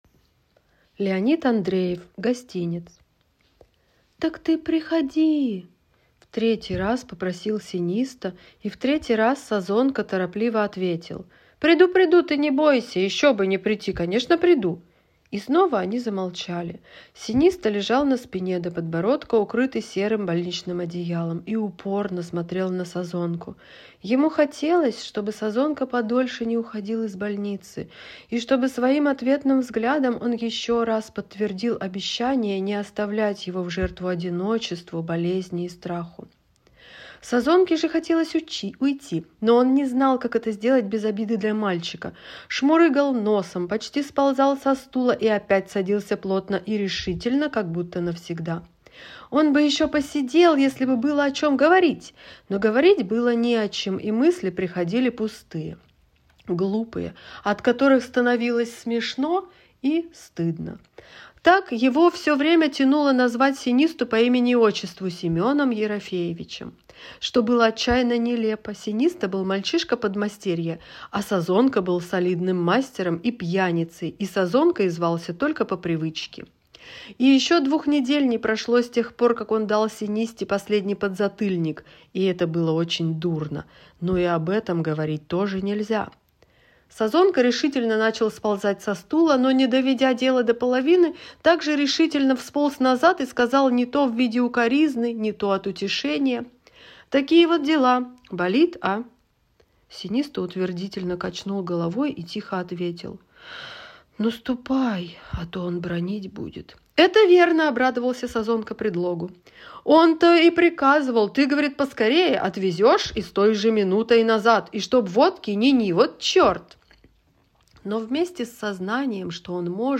Гостинец - аудио рассказ Андреева - слушать онлайн